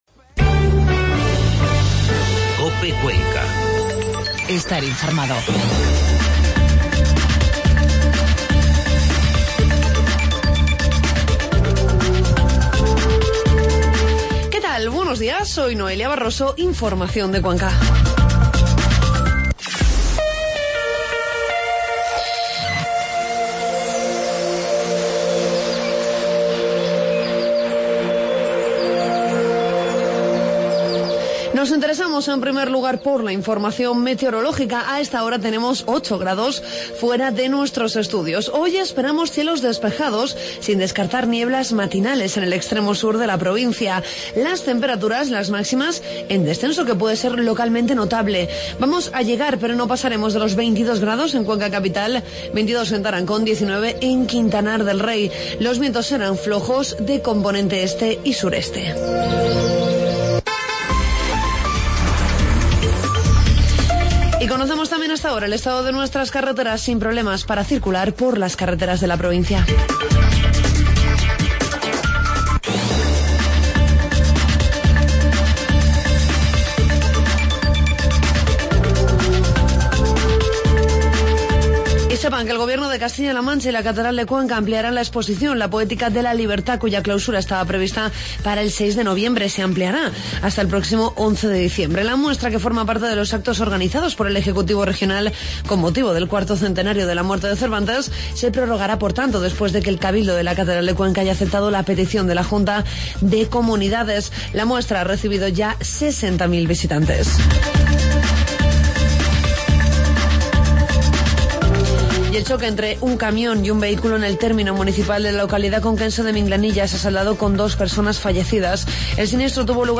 Informativo matinal COPE Cuenca